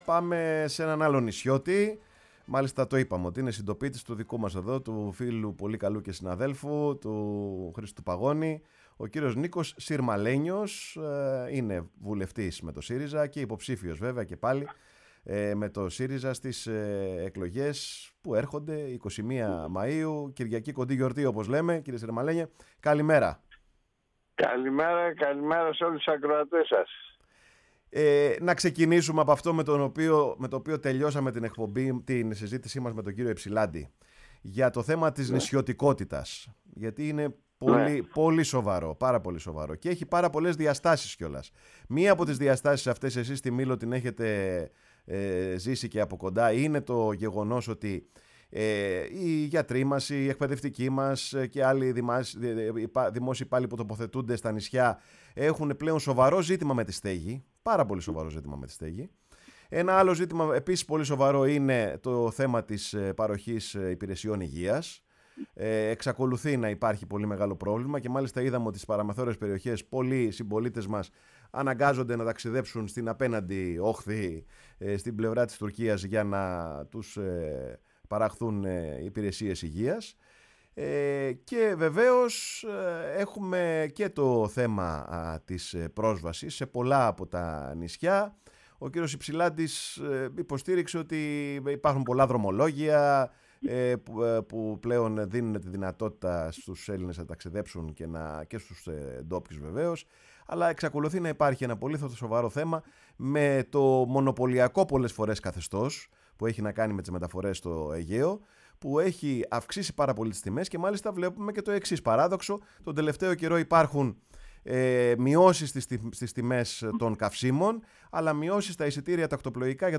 Η ΦΩΝΗ ΤΗΣ ΕΛΛΑΔΑΣ Παρε τον Χρονο σου ΕΝΗΜΕΡΩΣΗ Ενημέρωση ΣΥΝΕΝΤΕΥΞΕΙΣ Συνεντεύξεις Εκλογες 2023 Νικος Συρμαλενιος